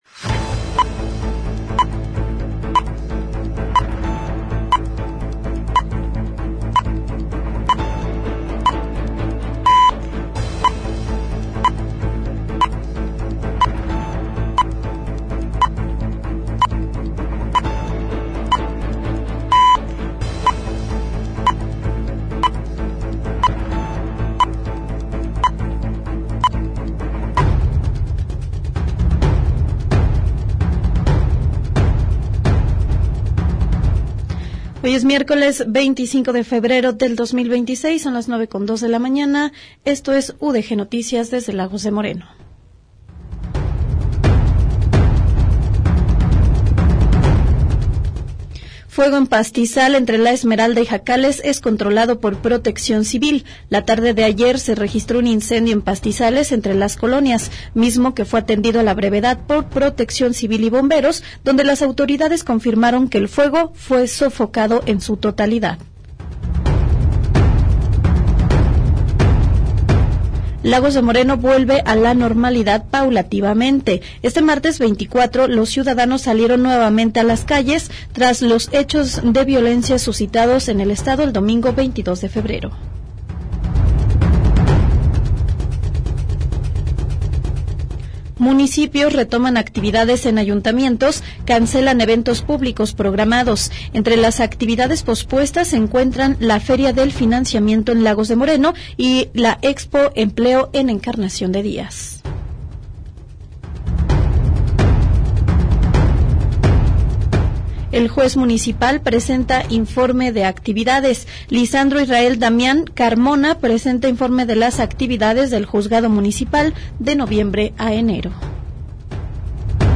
Espacio periodístico dedicado a recopilar, analizar y difundir los acontecimientos más relevantes de una comunidad específica. Ofrece cobertura puntual de los hechos más importantes a nivel local y regional.
GÉNERO: Informativo